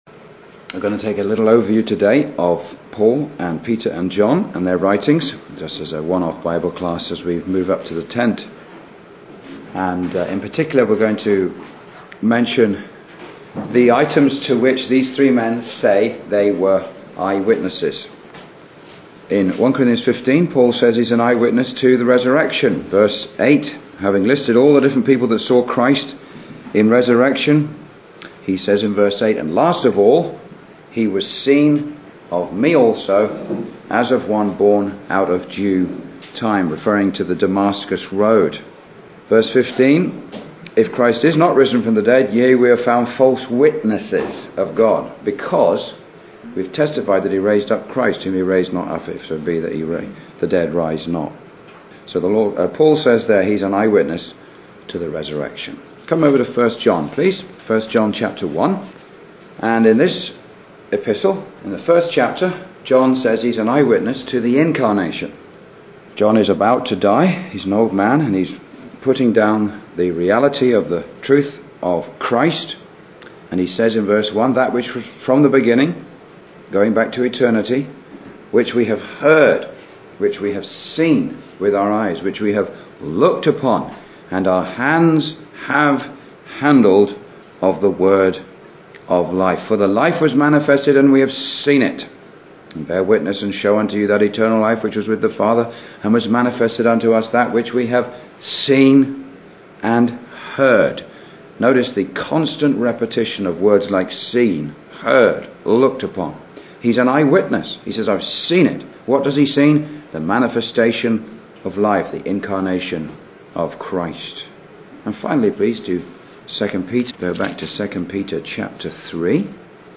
Individual sermons on the Lord Jesus